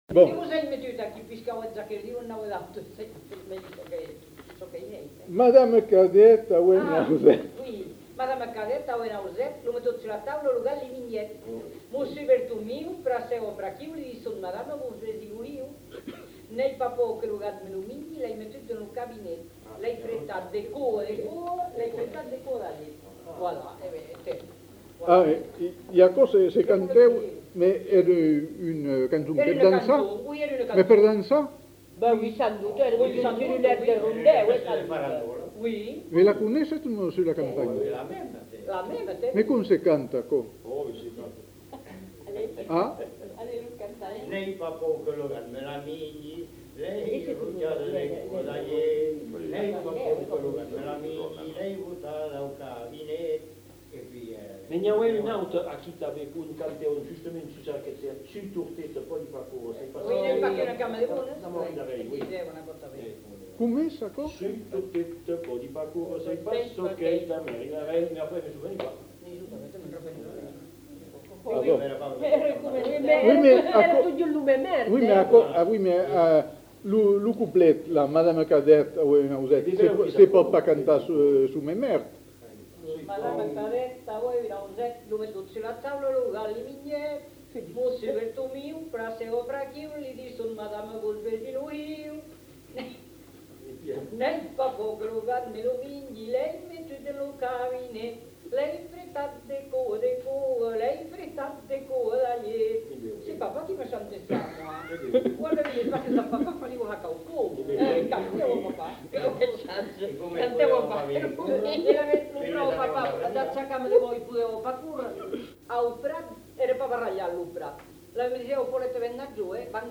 Lieu : Bazas
Genre : chant
Type de voix : voix de femme ; voix d'homme
Production du son : récité ; chanté
Danse : rondeau
Notes consultables : D'abord récité par une femme non identifiée puis un homme chante quelques vers.